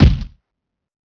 PBJ Kick - Boom.wav